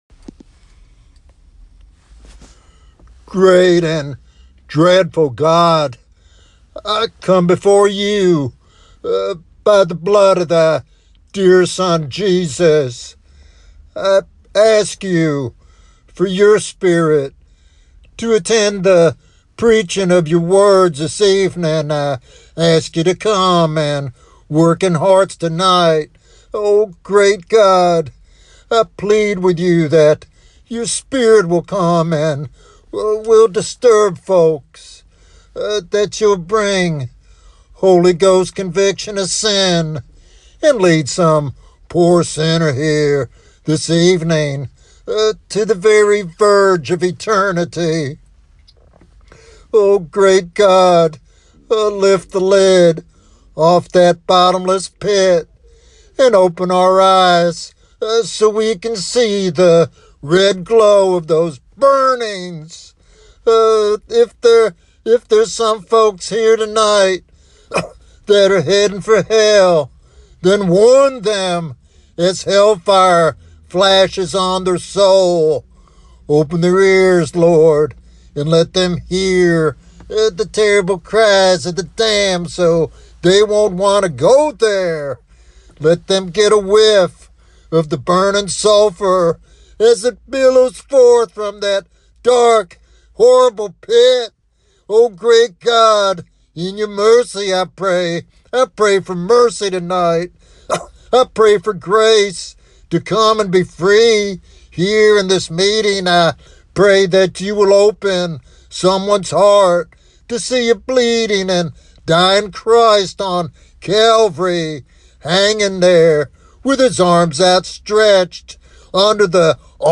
This evangelistic sermon calls for repentance and faith, emphasizing God's mercy and the hope found in Christ's advocacy.